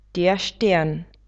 Hvezda (640x604)hvězda der Stern [štern]